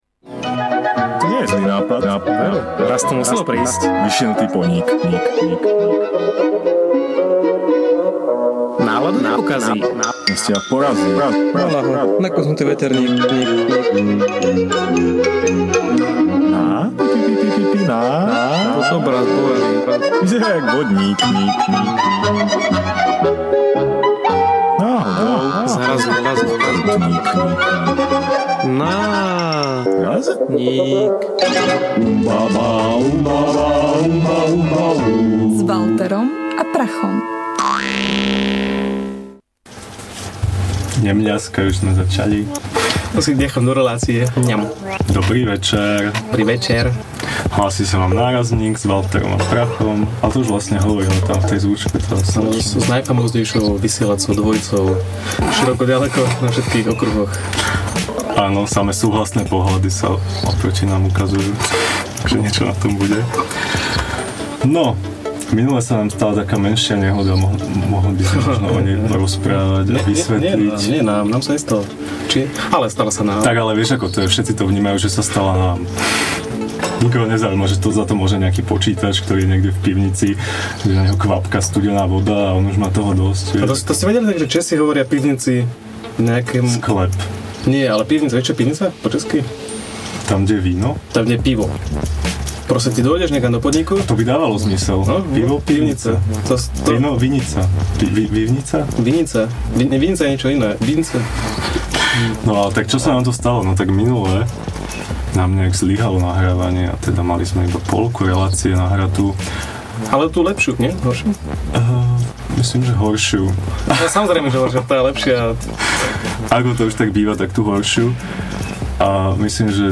Podcasty NÁRAZNÍK / Ukecaná štvrtková relácia rádia TLIS NÁRAZNÍK #7 / Iné svety 10. novembra 2011 Nárazník, číslo šťastné: vitajte do imaginária. Každý z nás má svoj svet, viac či menej uletený.